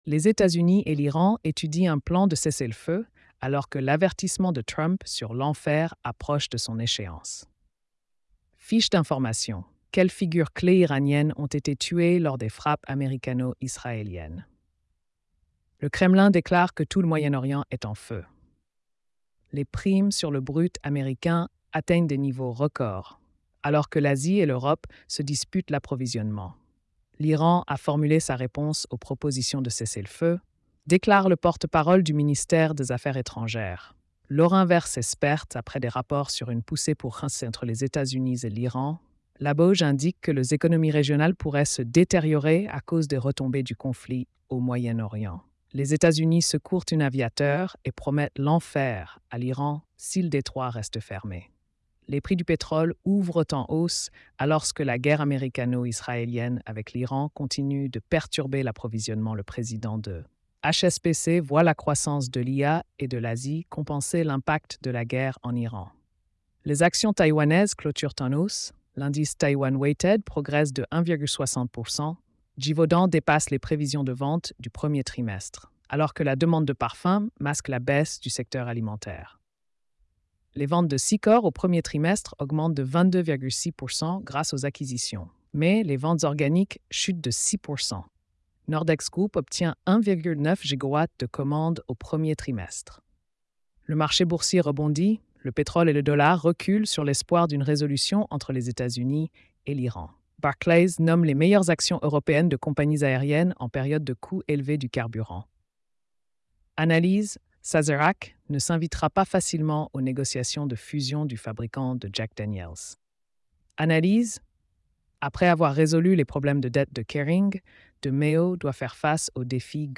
🎧 Résumé économique et financier.